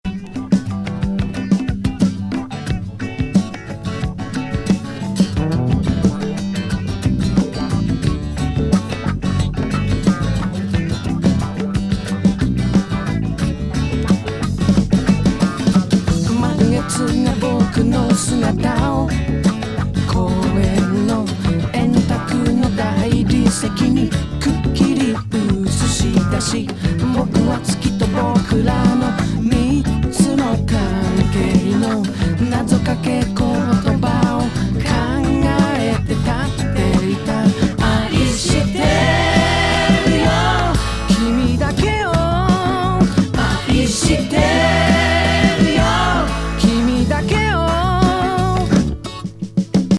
ジャズ・シンガー
非常にいい塩梅の怪しげムード